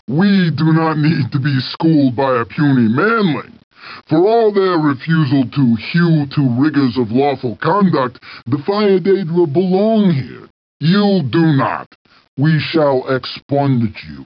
Voice line of Reply 5 from a Frost Daedra in Battlespire.